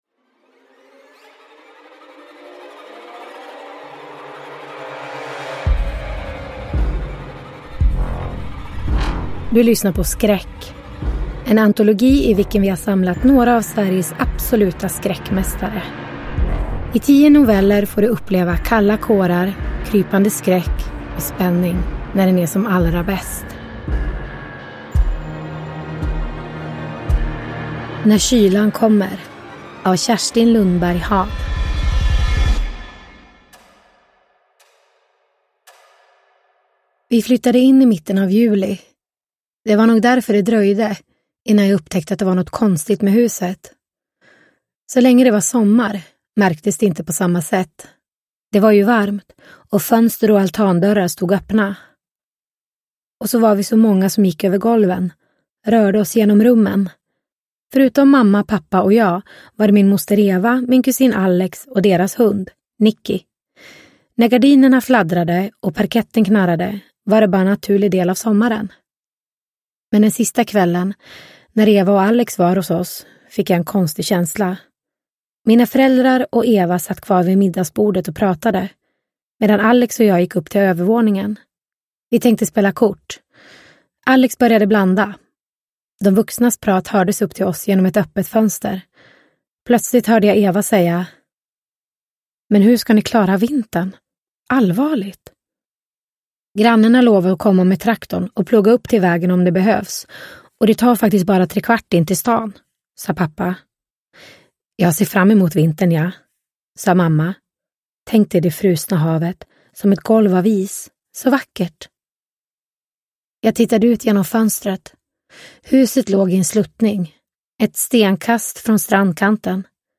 Skräck - När kylan kommer – Ljudbok – Laddas ner